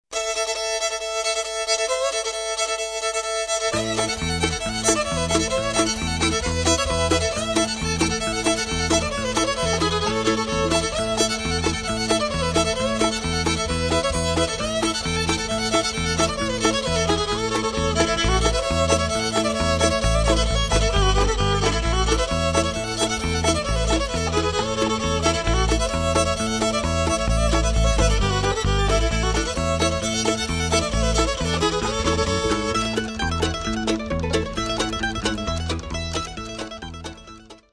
Opět se tedy jedná o CD s hudbou k americkým country tancům.